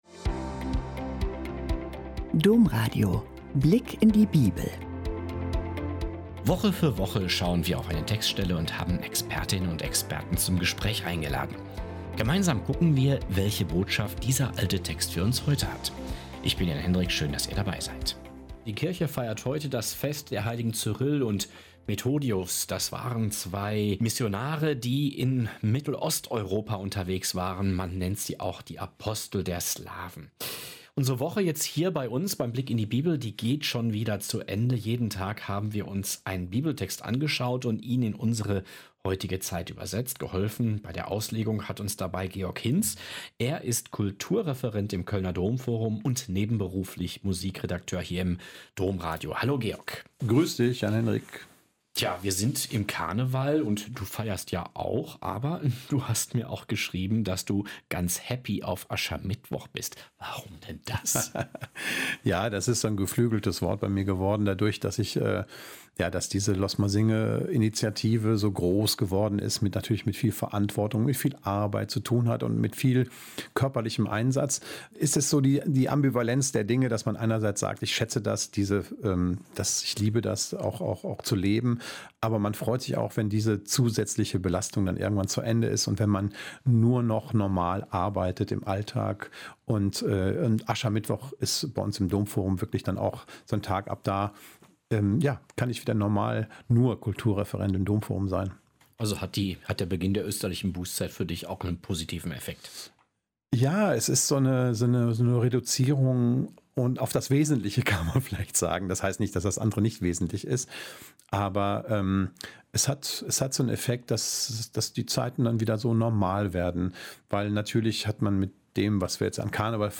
Christlicher Glaube soll den Menschen auf Augenhöhe begegnen und der Gesellschaft dienen. Eine ruhige, klare Botschaft zum Ende der Woche – und ein starker Übergang vom Feiern zum Innehalten.